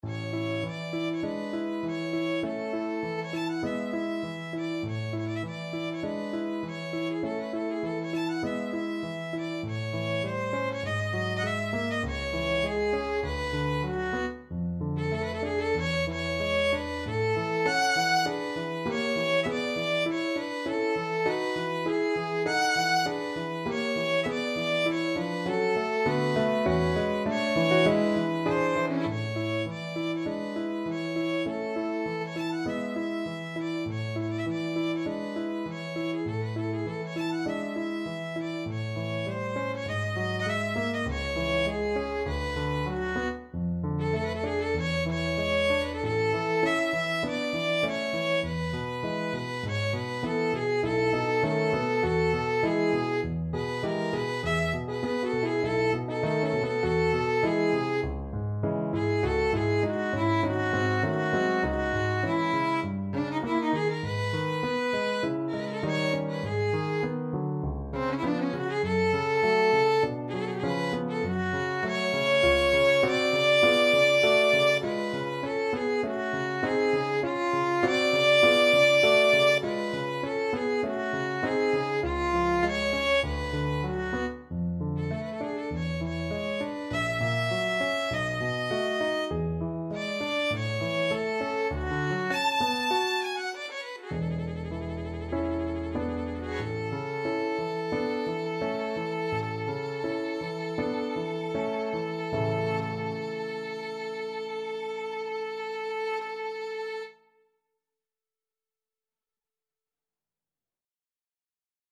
Violin
4/4 (View more 4/4 Music)
A major (Sounding Pitch) (View more A major Music for Violin )
Classical (View more Classical Violin Music)